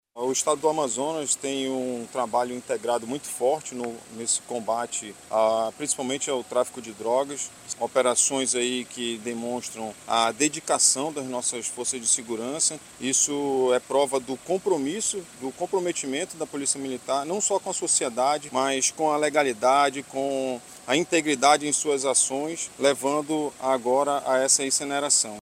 O comandante-geral da PMAM, Klinger Paiva, destaca a importância da integração entre as forças de segurança do estado para alcançar esses resultados.